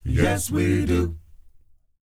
ACCAPELLA 1G.wav